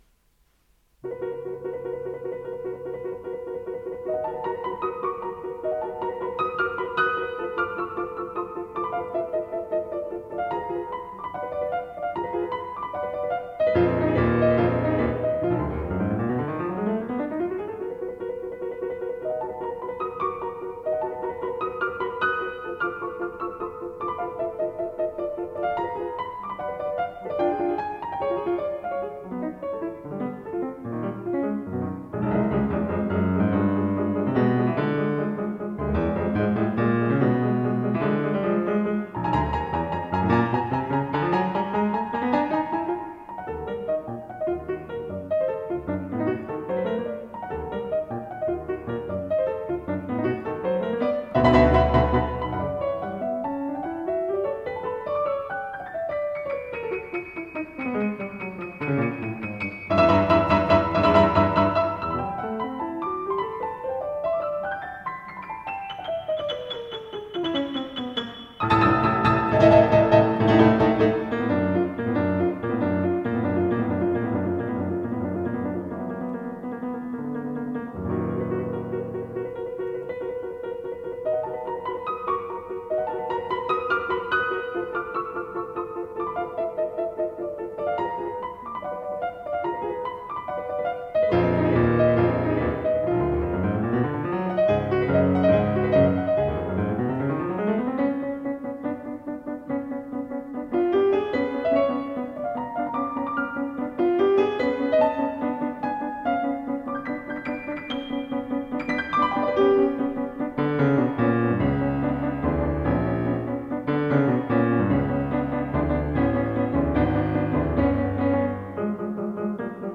Heinrich Sutermeister: Sonatine in E-flat for Piano Solo (Presto)